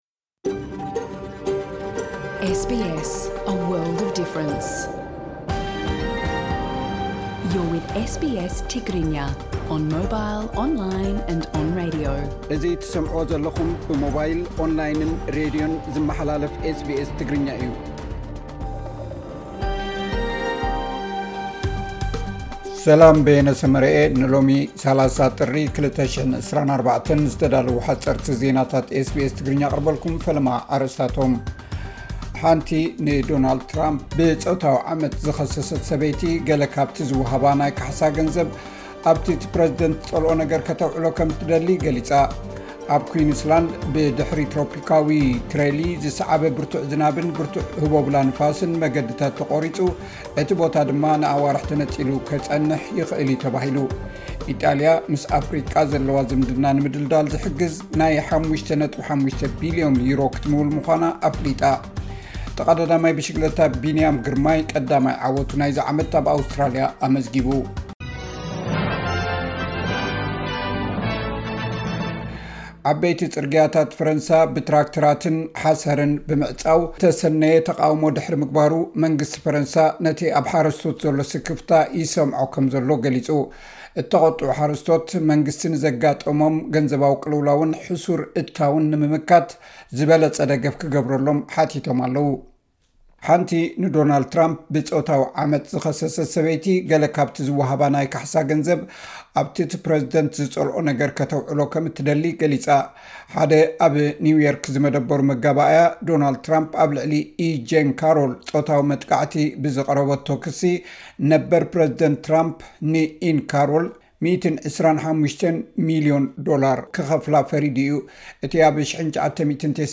ጣልያን ን ኣፍሪቃ 5.5 ቢልዮን ክትምውል እያ። ሓጸርቲ ዜናታት ኤስ ቢ ኤስ ትግርኛ (30 ጥሪ 2024)